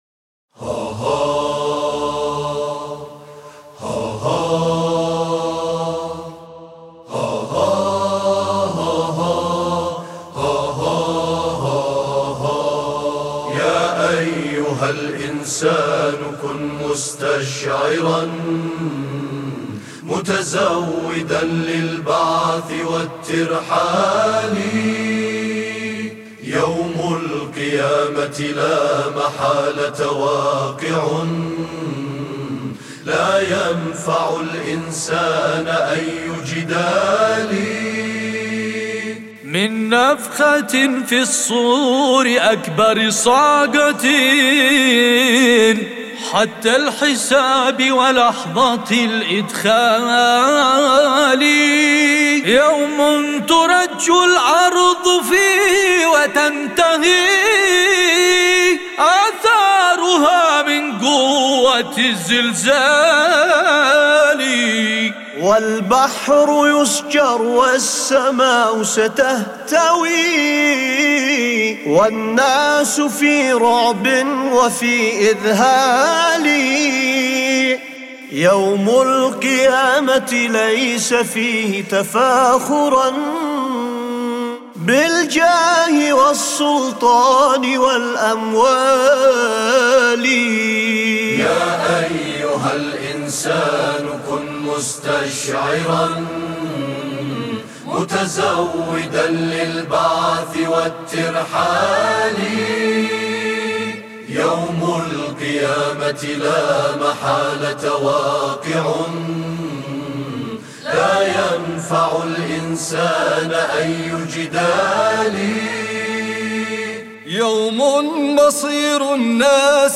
بدون موسيقى mp3